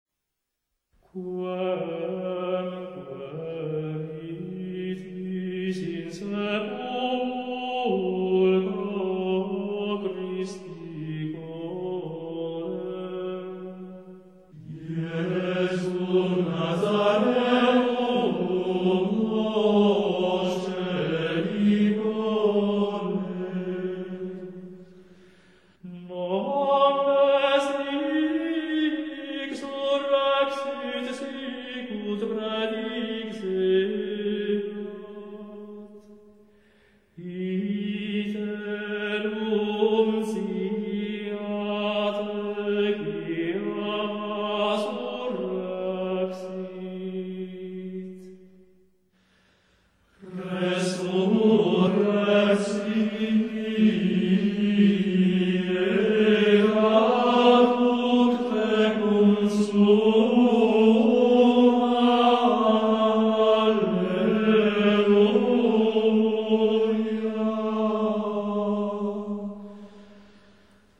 Пасхальные песнопения.